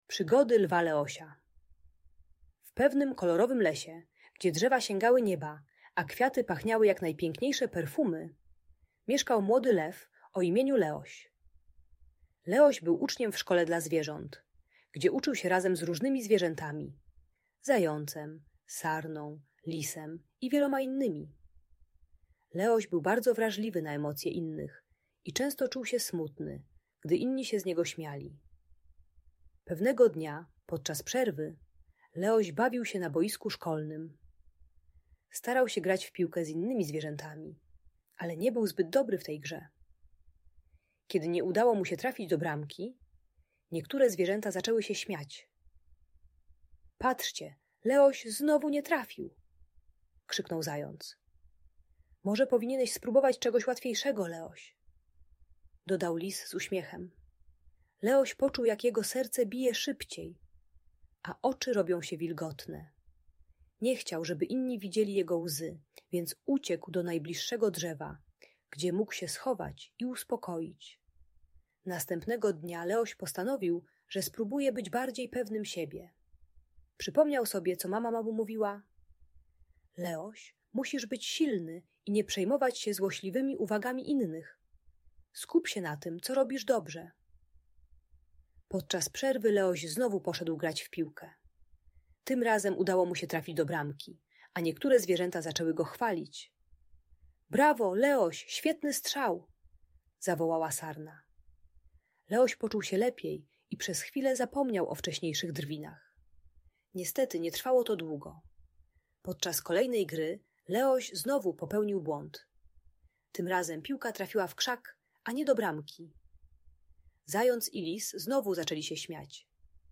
Uczy techniki budowania pewności siebie poprzez pozytywne afirmacje i skupienie na własnych mocnych stronach. Audiobajka o radzeniu sobie z drwinami i krytyką rówieśników.